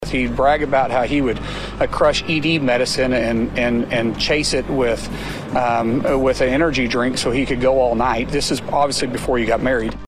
CLICK HERE to listen to commentary from Oklahoma Senator Markwayne Mullin.